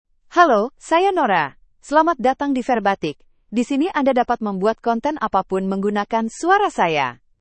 NoraFemale Indonesian AI voice
Nora is a female AI voice for Indonesian (Indonesia).
Voice sample
Listen to Nora's female Indonesian voice.
Nora delivers clear pronunciation with authentic Indonesia Indonesian intonation, making your content sound professionally produced.